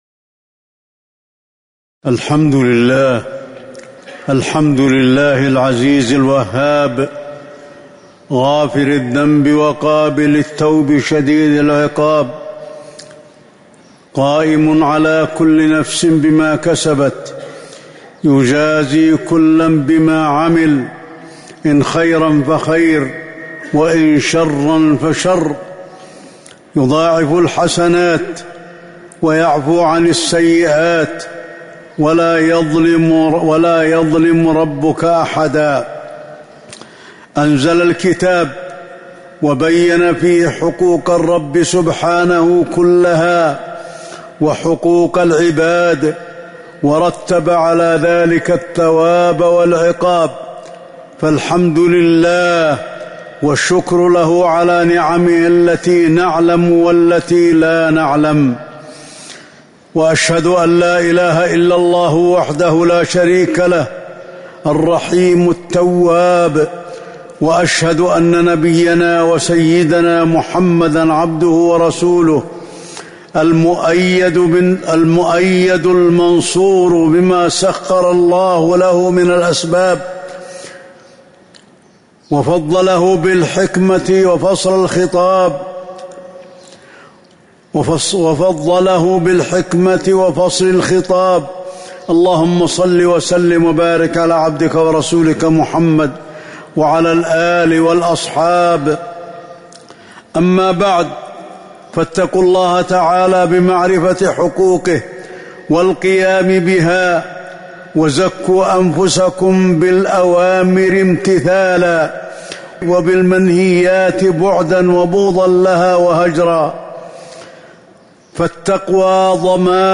تاريخ النشر ١٨ ربيع الأول ١٤٤٤ هـ المكان: المسجد النبوي الشيخ: فضيلة الشيخ د. علي بن عبدالرحمن الحذيفي فضيلة الشيخ د. علي بن عبدالرحمن الحذيفي عبادة الله عز وجل